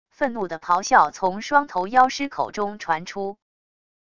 愤怒的咆哮从双头妖狮口中传出wav音频